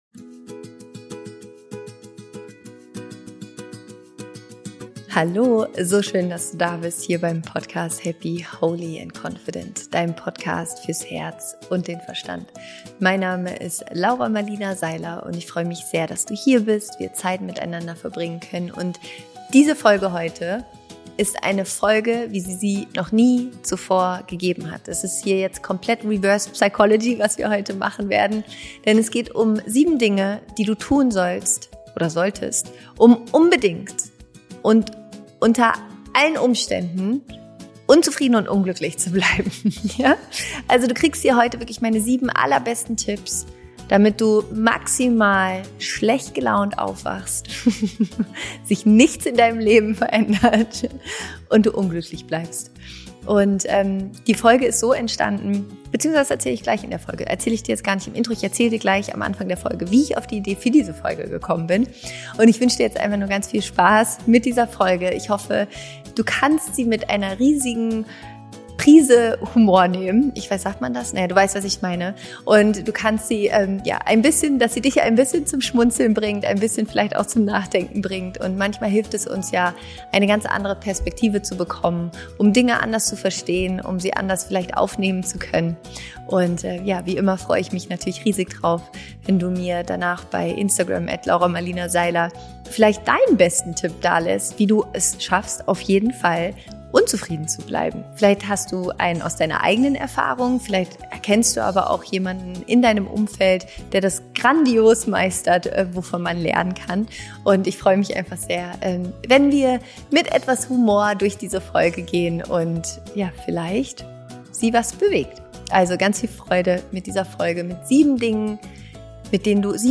Diese Folge aufzunehmen hat mir so viel Spaß gemacht – ich musste manchmal komplett neu anfangen, weil ich so lachen musste!